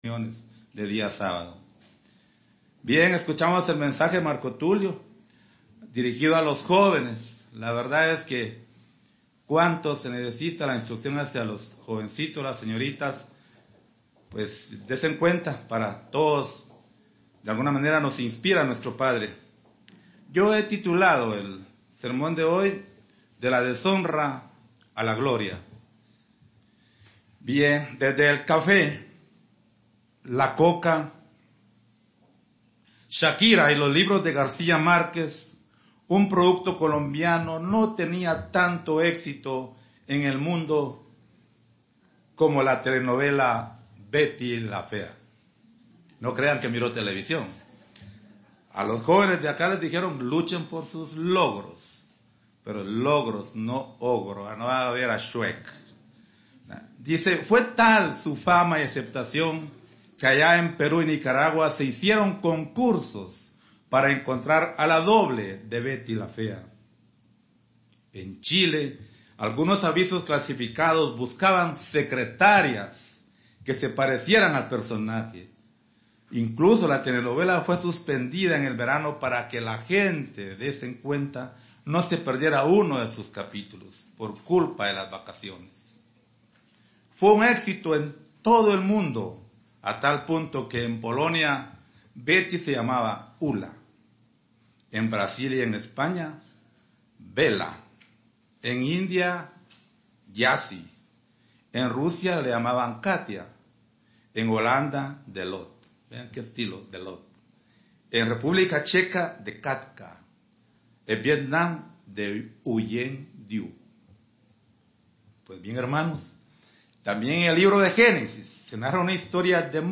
El proceso que Dios ha iniciado con aquellos a quienes ha decidido llamar, tiene un desenlace de proporciones sin igual. Mensaje entregado el 12 de enero de 2019